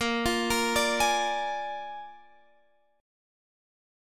Listen to A#7 strummed